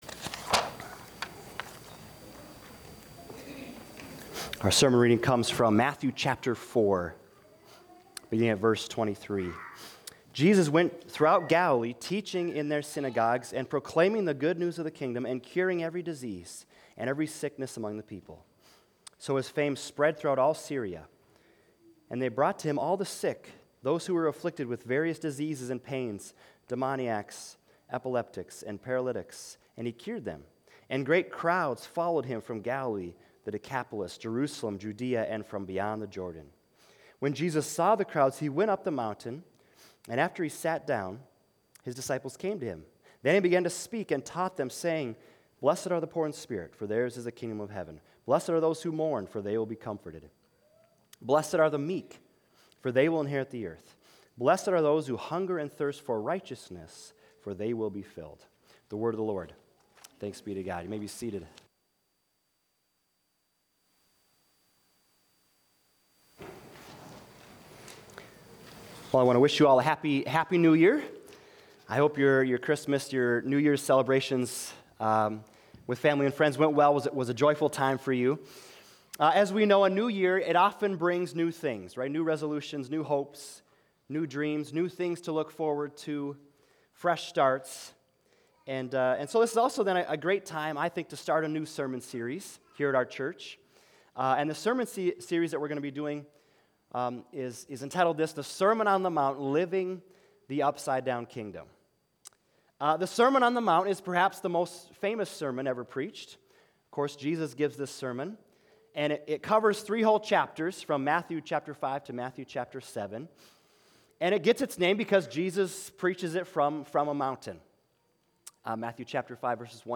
Sermons 2026 - Bethesda Lutheran Church
Jan-4,-2026---The-Sermon-on-the-Mount---Introduction.MP3